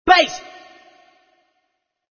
misc_vocal03.mp3